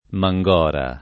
[ ma jg0 ra ]